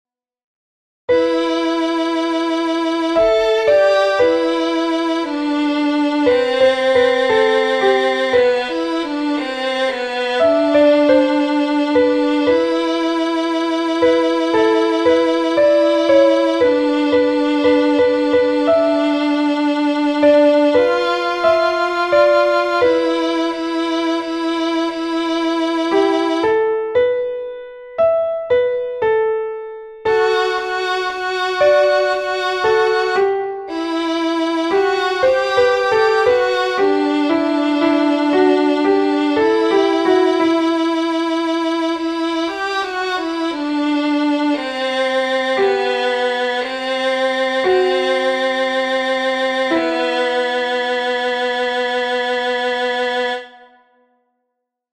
In these recordings, the higher voice is a piano, lower voice is a violin. If it is helpful, I included a soloist part, which sounds like an oboe.
THESE ARE QUITE LOUD.
SOPRANO AND ALTO 2, PAGE 22